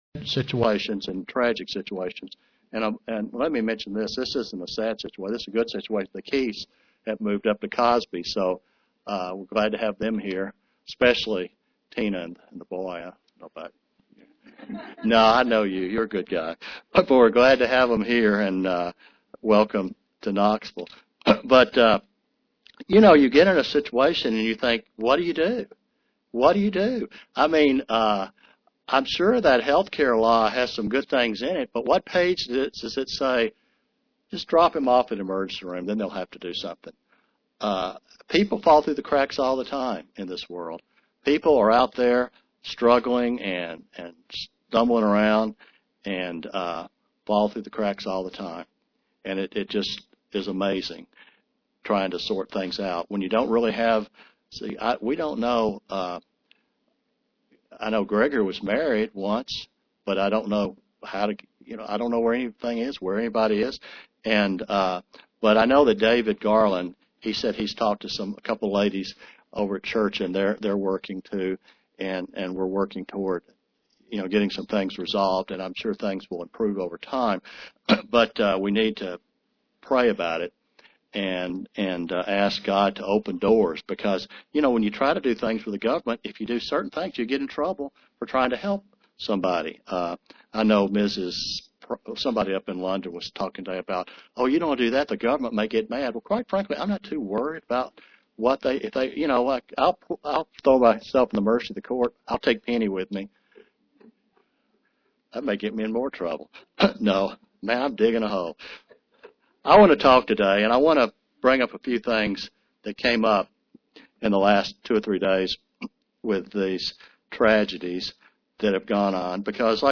Given in Knoxville, TN
Print Leaven equals sin and unleavened equals God's righteousness UCG Sermon Studying the bible?